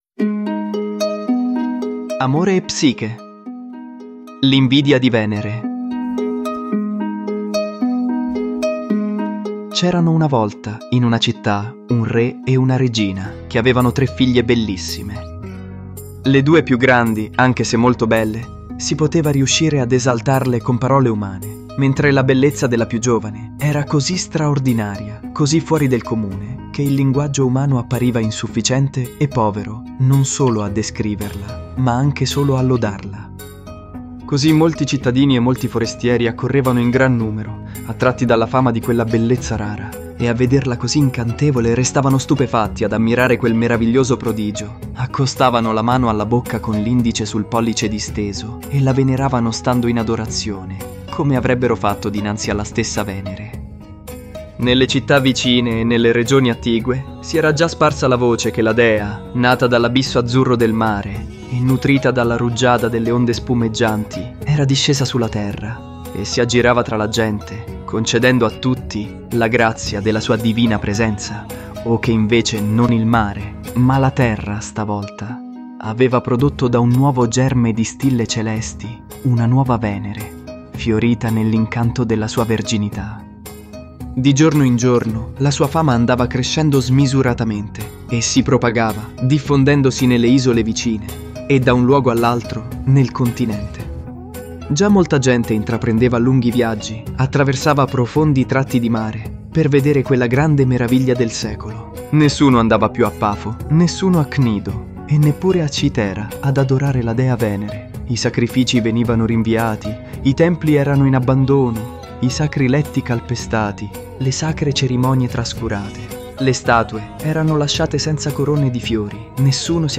I nostri bravi attori vogliono farvi un piccolo regalo: la favola di Amore e Psiche tratta dalle "Metamorfosi" di Apuleio, a puntate.
La colonna sonora include una cover strumentale di "Bad Romance" di Lady Gaga realizzata dai Courseval.
The soundtrack includes an instrumental cover of Lady Gaga's "Bad Romance" performed by Courseval.